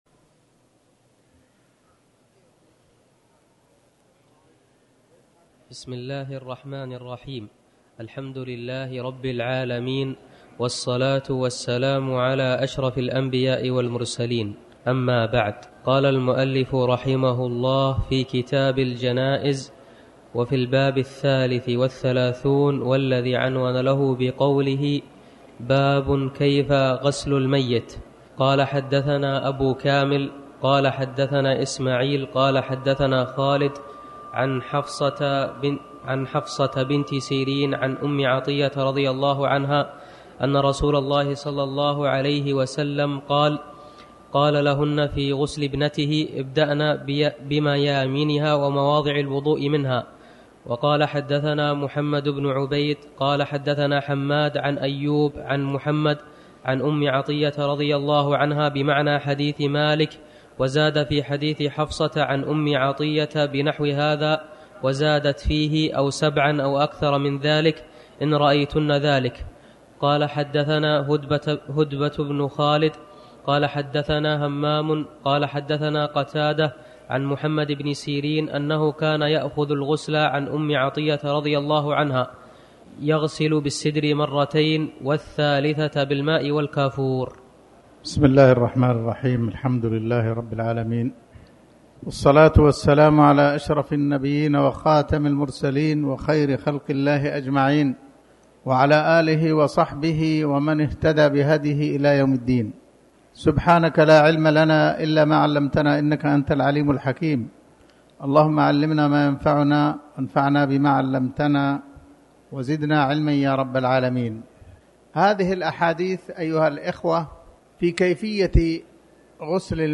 تاريخ النشر ٨ صفر ١٤٤٠ هـ المكان: المسجد الحرام الشيخ